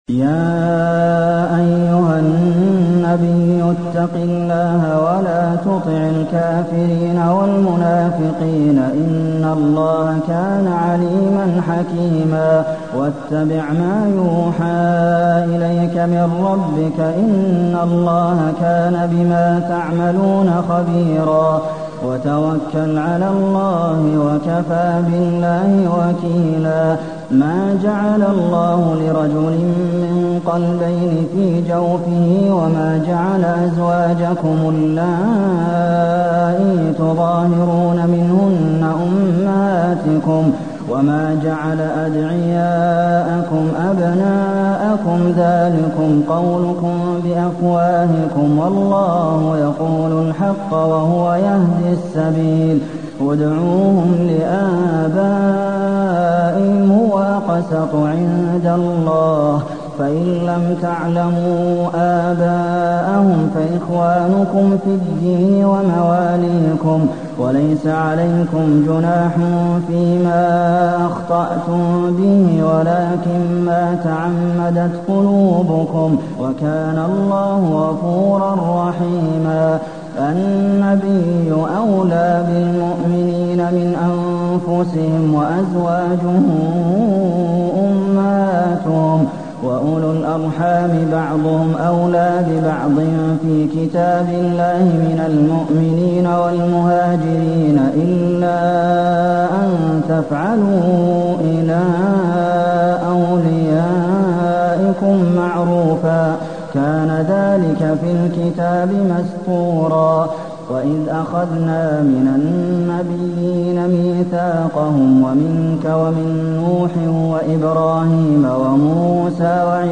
المكان: المسجد النبوي الأحزاب